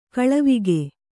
♪ kaḷavige